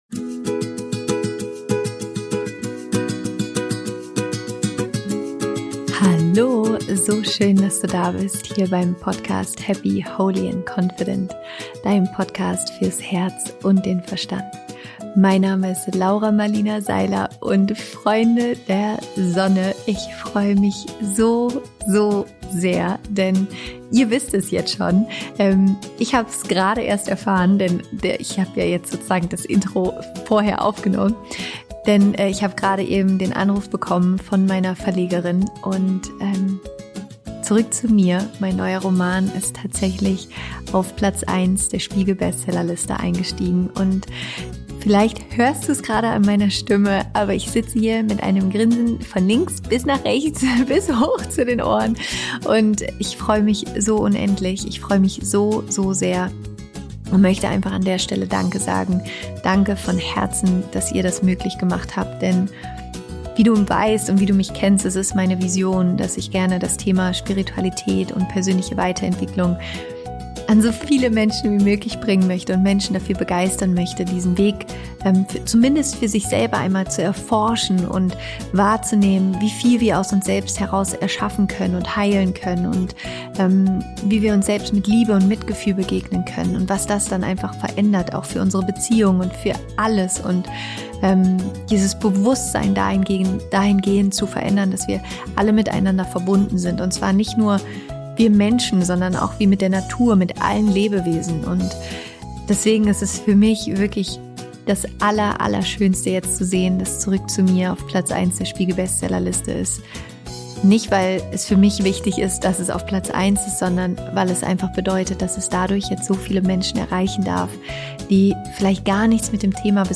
So löst du Trigger in deiner Beziehung auf - Interview Special mit Stefanie Stahl
Stefanie ist Autorin, Speakerin, Psychologin und Expertin für die innere Kindarbeit.